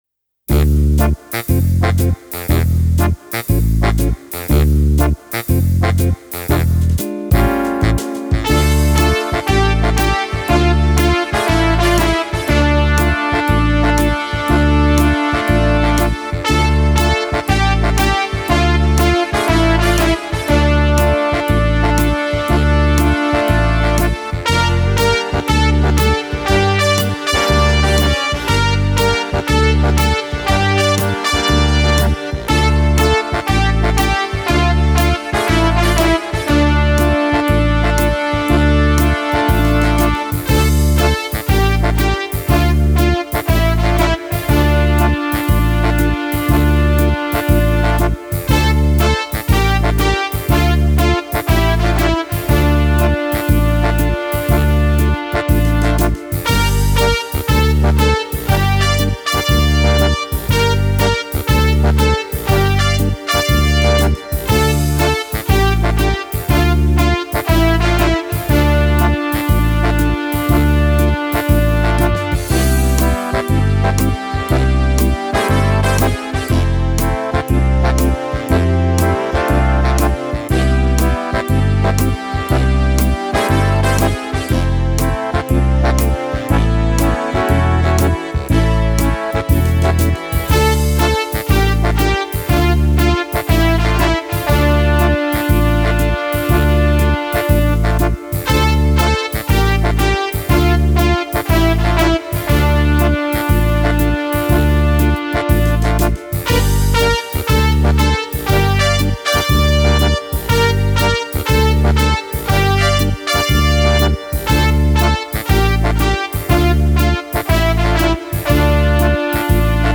Mandag 13. desember 2021:  FANCY GANGE – INSTRUMENTAL (Sang nr 103 – på 103 dager)
programmering og alle keyboards
Dette er en instrumental skisse jeg har redigert.